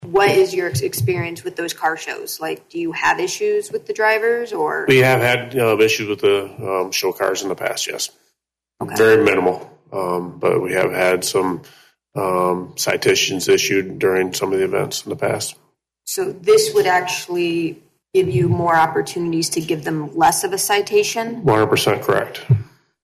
Public Safety Director Joe Scheid told Council Member Emily Rissman the ordinance would give officers more opportunities to issue a lesser ticket than one for reckless or careless driving.